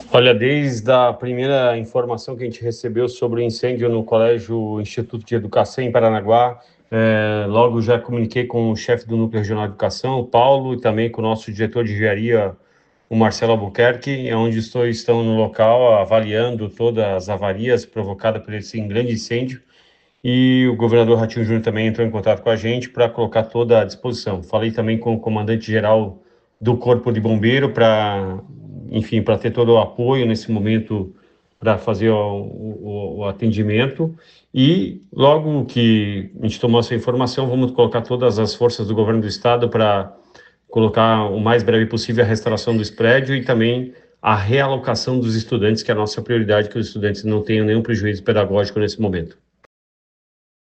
Sonora do secretário da Educação, Roni Miranda, sobre a atuação do Governo do Paraná após o incêndio no Instituto Estadual de Educação de Paranaguá